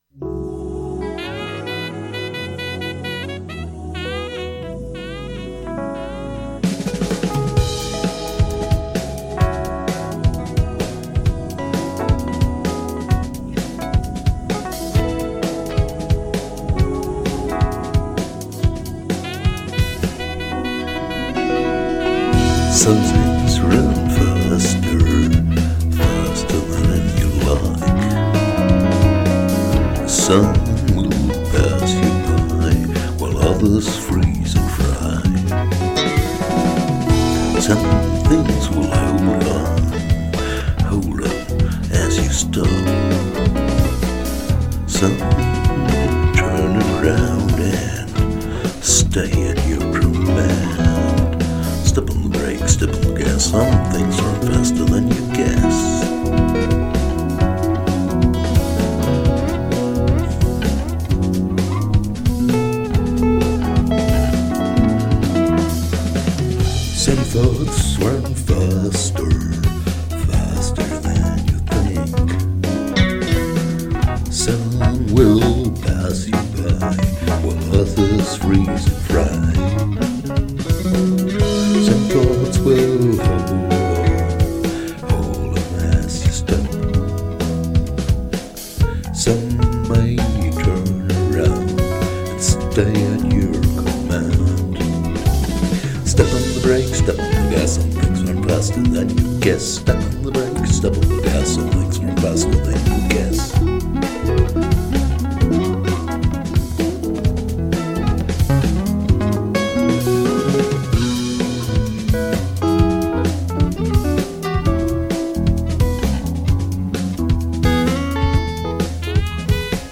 Some things run faster So, mal schauen, was Du von diesem jazzy approach hältst , etwas E Piano, Gitarre(n) und Vocals, es gibt auch nen Text dazu.